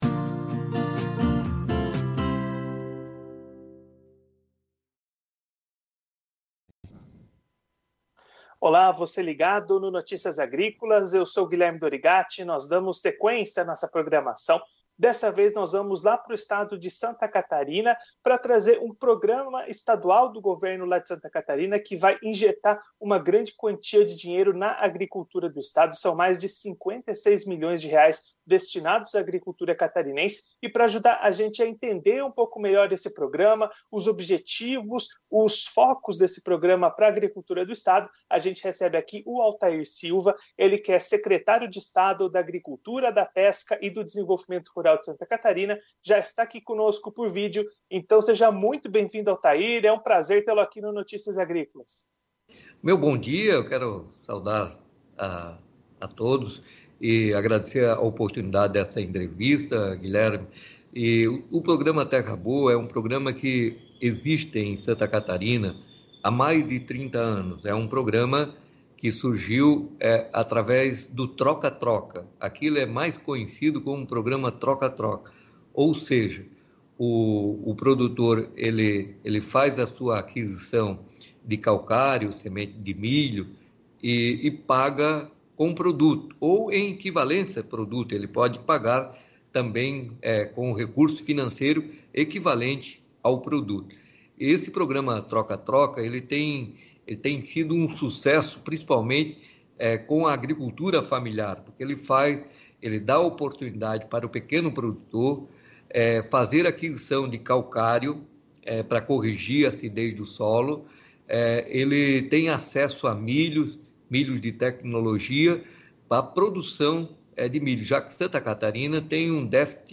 Confira a íntegra da entrevista com o secretário de Estado da Agricultura, da Pesca e do Desenvolvimento Rural de Santa Catarina no vídeo.